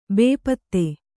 ♪ bēpatte